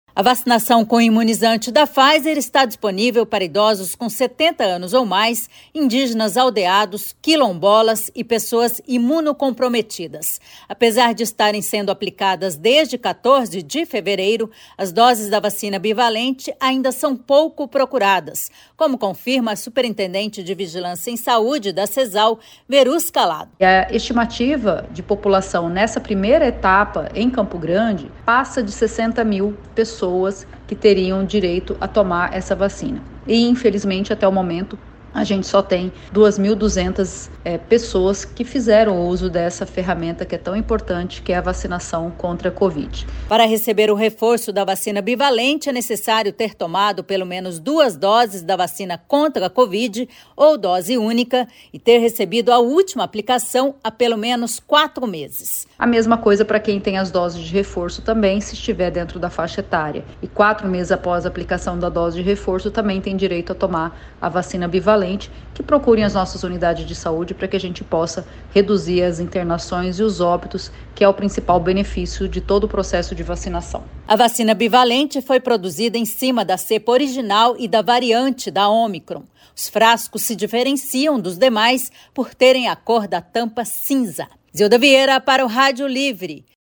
Entenda na reportagem